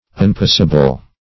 Unpossible \Un*pos"si*ble\